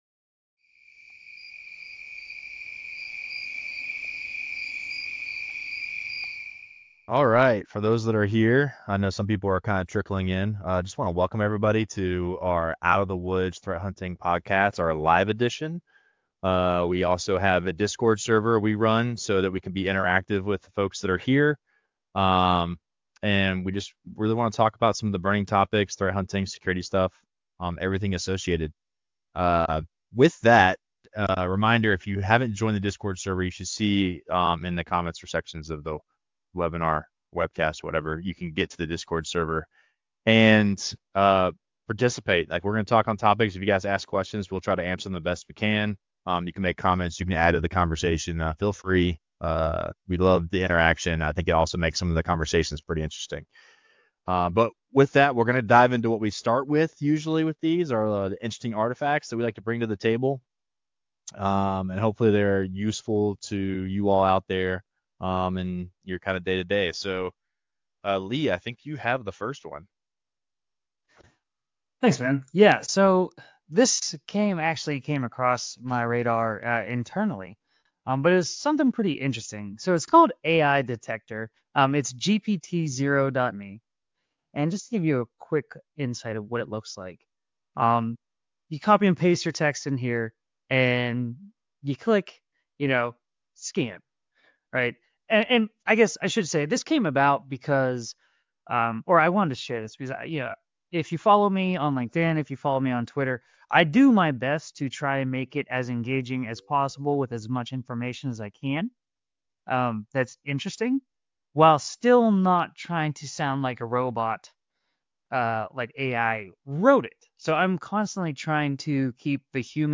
[LIVE] The Ideal Outcome: The Gift of a Well-Crafted Threat Hunt